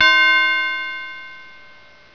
CHIME_A.WAV